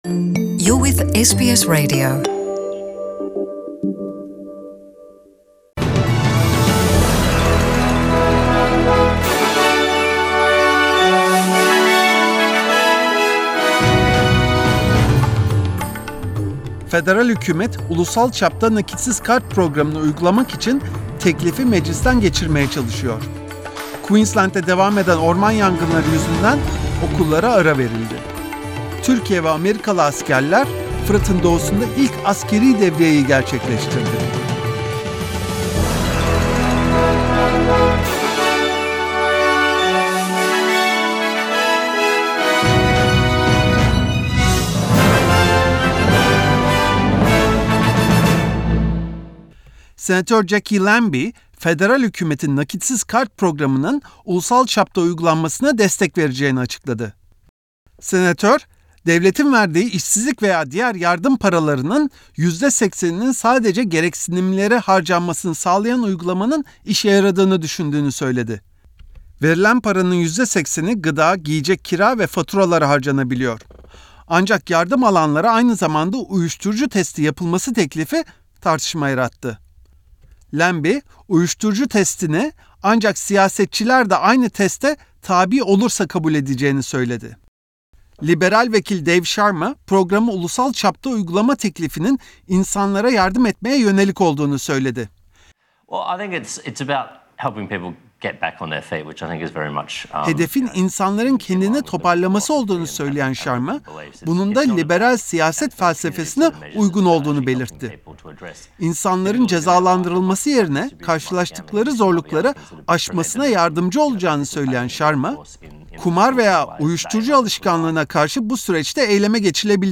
SBS Türkçe Programı'ndan Avustralya, Türkiye ve dünyadan haberler. BAŞLIKLAR * Federal hükümet, ulusal çapta nakitsiz kart uygulamasını getirmeye karar verdi *Queensland’de devam eden orman yangınları yüzünden okullara ara veridli.